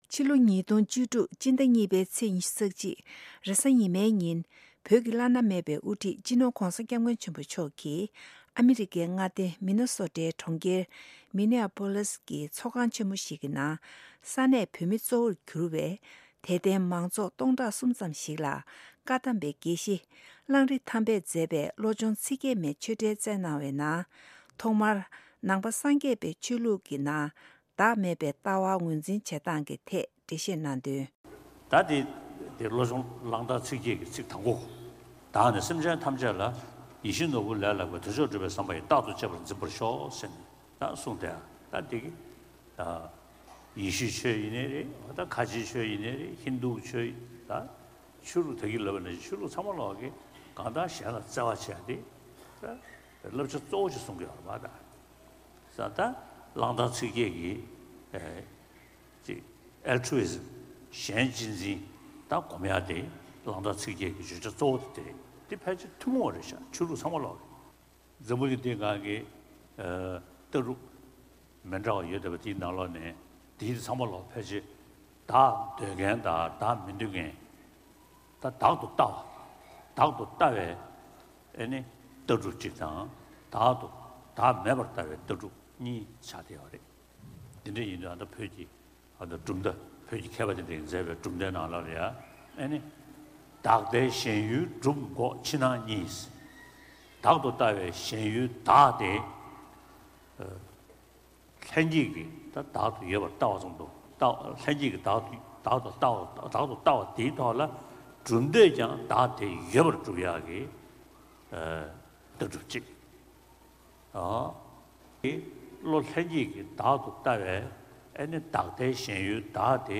སྤྱི་ནོར་༧གོང་ས་༧སྐྱབས་མགོན་ཆེན་པོ་མཆོག་གིས་ཉེ་དུས་ཨ་མི་རི་ཀའི་མི་ནི་སོ་ཊའི་མངའ་སྡེའི་ནང་གནས་འཁོད་ས་གནས་བོད་མི་གཙོ་བོར་གྱུར་བའི་དད་ལྡན་མང་ཚོགས་ལ་ཆོས་འབྲེལ་དང་ བླང་འདོར་གྱི་བཀའ་སློབ་གནང་བའི་དུམ་བུ་གཉིས་པ་དེ་གསན་རོགས་གནང་།